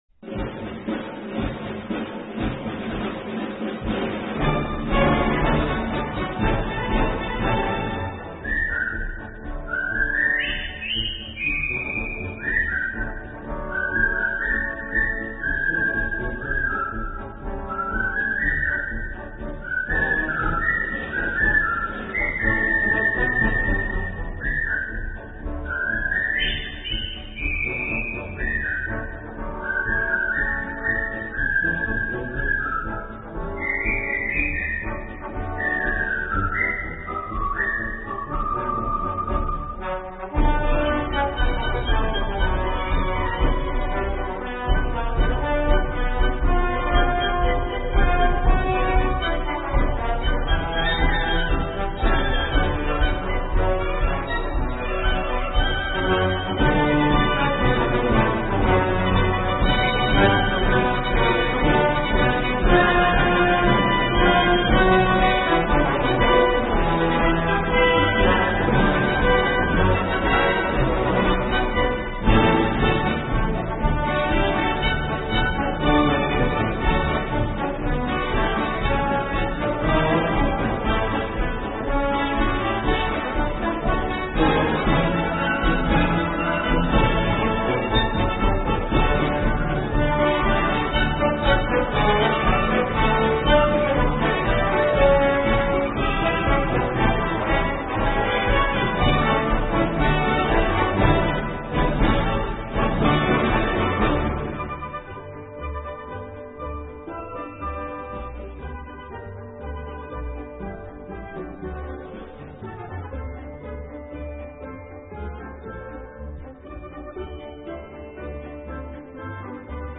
звучит в исполнении американского оркестра